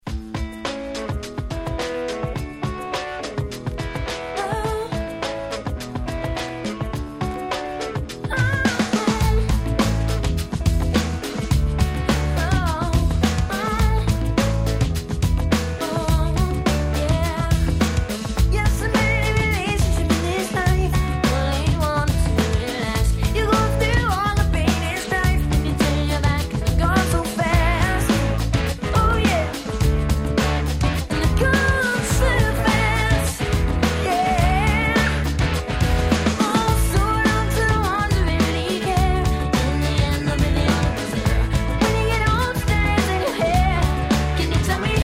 ※試聴ファイルは別の盤から録音してあります。
アメリカ、オクラホマ生まれの兄弟ユニット。